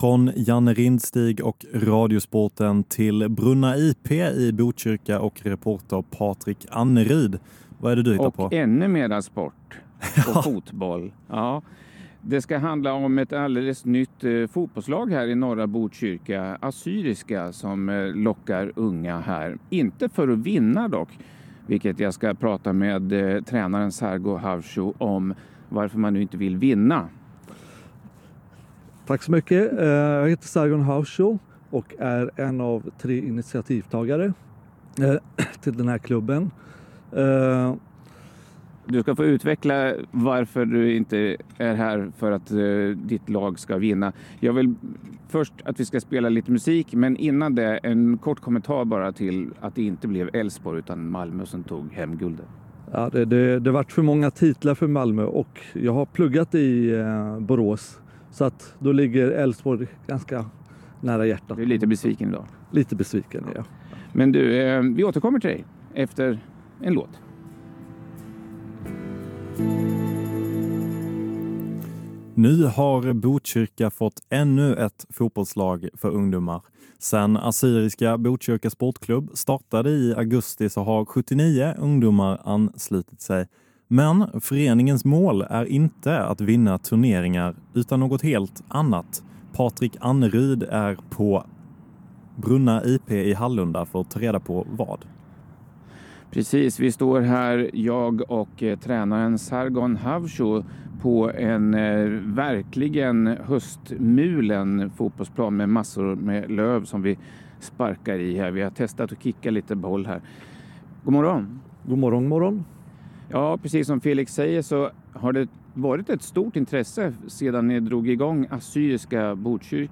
Sveriges Radio P4 intervjuar Assyriska Botkyrka.